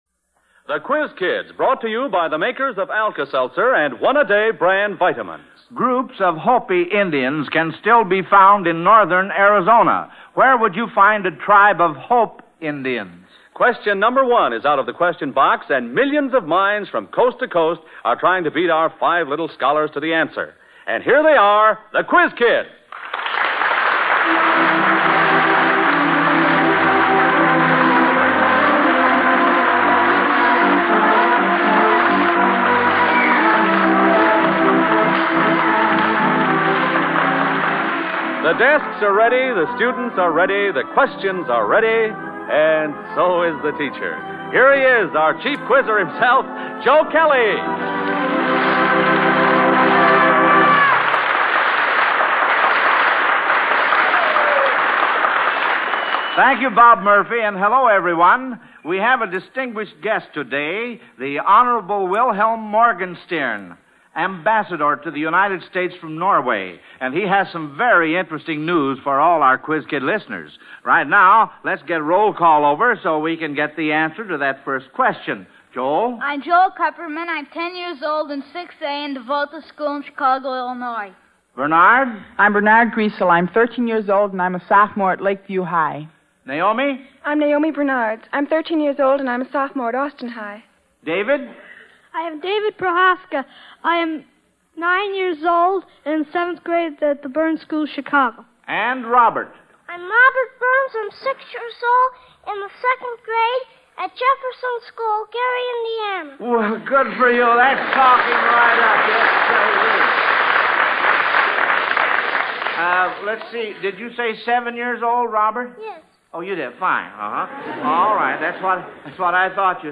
The Quiz Kids Radio Program